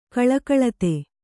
♪ kaḷakaḷate